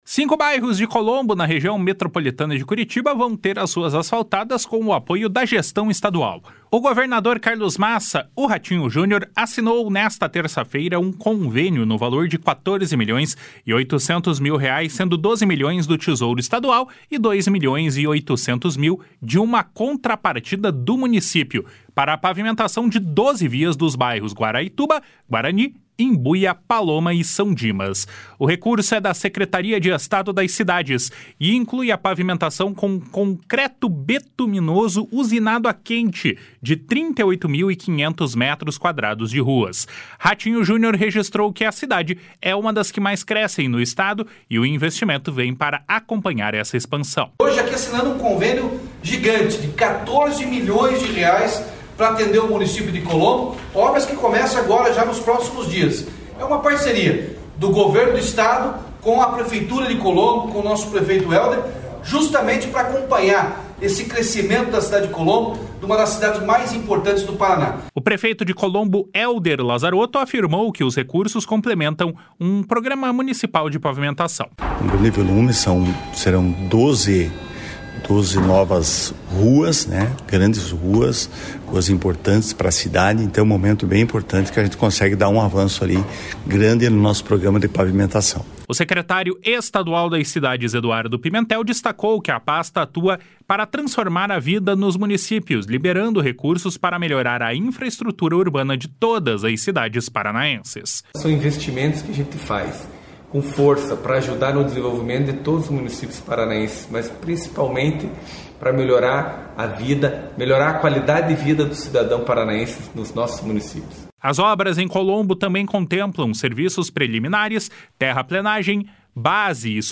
// SONORA RATINHO JUNIOR //
// SONORA HELDER LAZAROTTO //